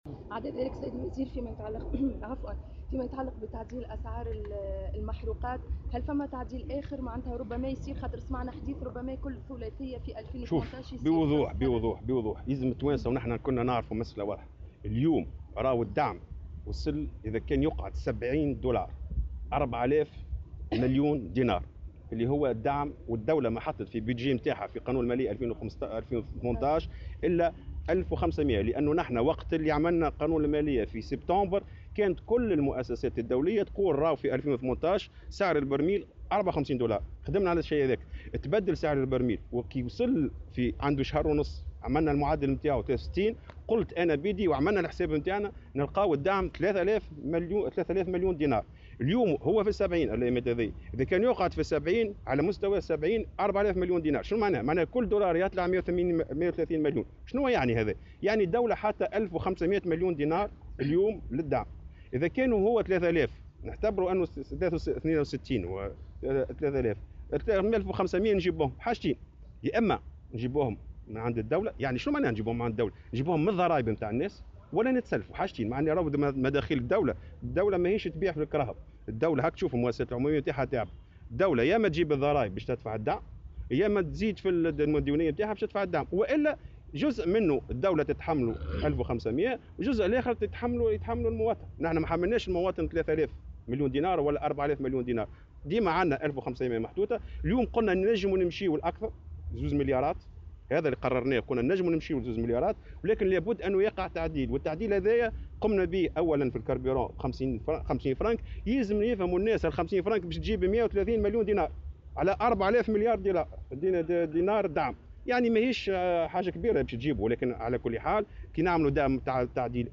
وجاءت هذه التصريحات، على هامش ندوة انتظمت اليوم في تونس العاصمة تتعلّق بالشروع في تنفيذ مخطط حوكمة المنشآت والمؤسسات العمومية.